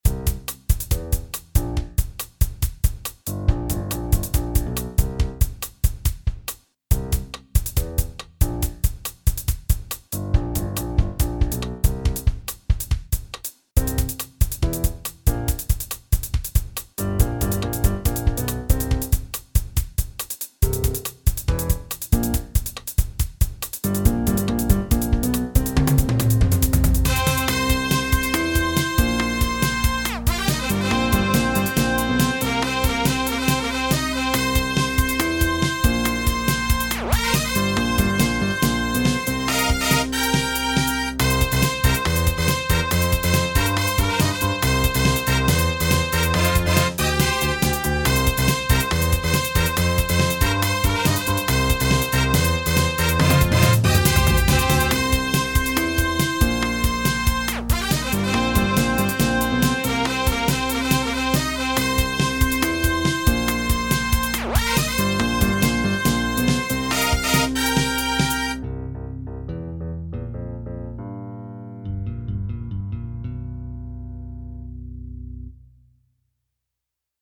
I REALLY LIKE THIS ONE I always wanted to use a brass with this I can cross it from my list :D